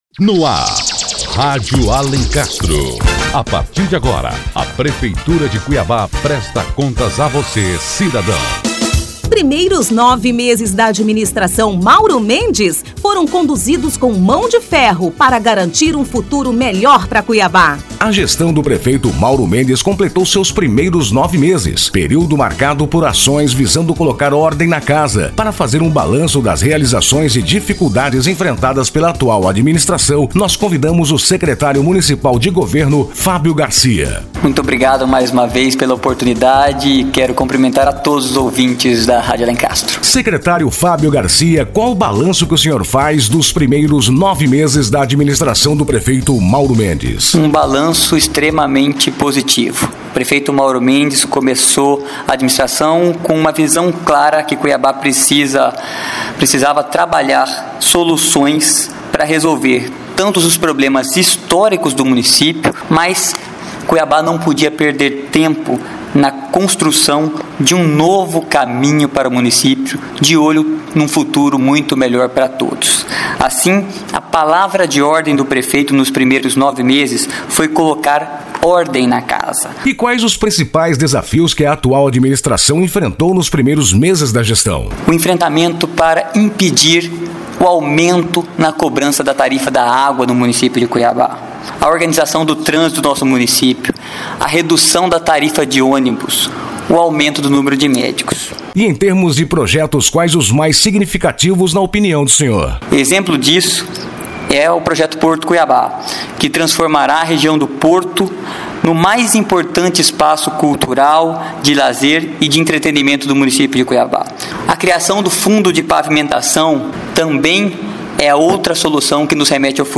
Ouça a entrevista com o Scretário Municipal de Governo, Fábio Garcia, e fique por dentro das melhorias e dificuldades...